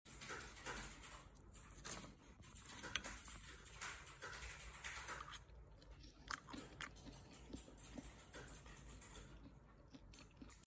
Potatoes Fried In Olive Oil Sound Effects Free Download